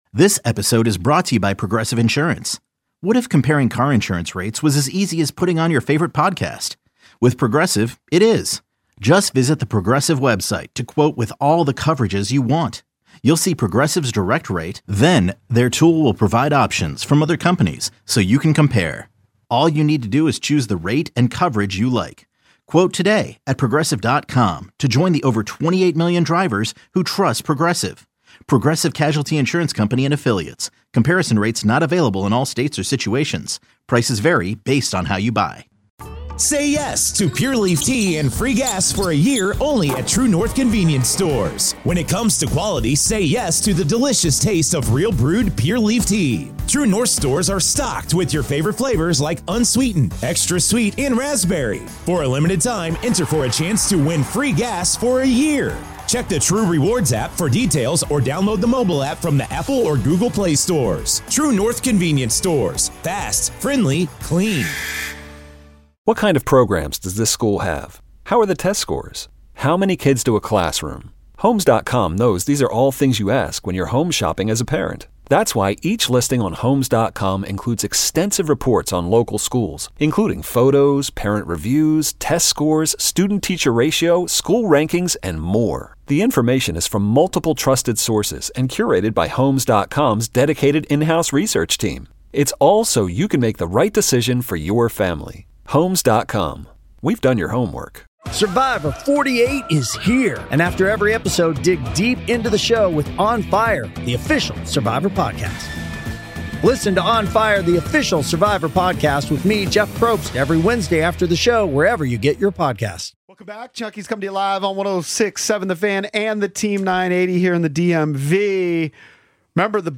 Interviews on WJFK/WTEM